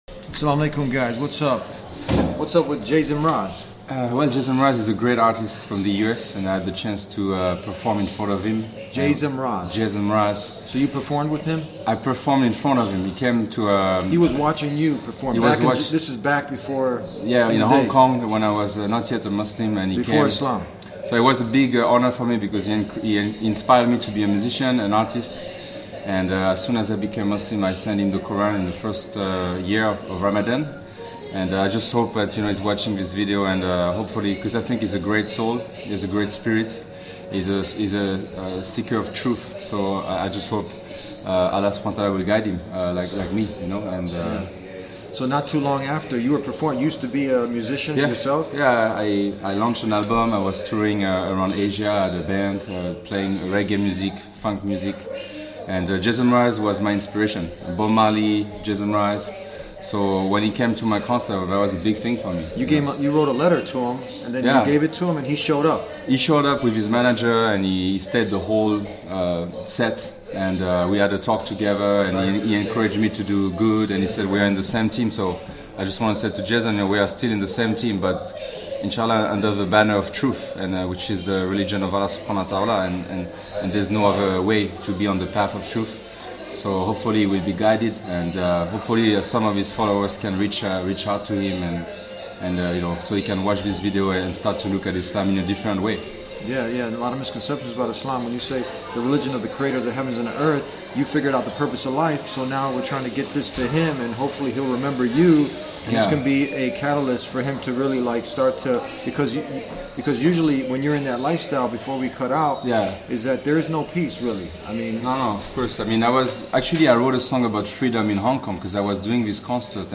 In this heartfelt episode, a former musician who once performed in front of the legendary Jason Mraz sends a special message to his old friend, hoping that the same God who guided him from the music industry to Islam will also guide one of the artists who inspired his career.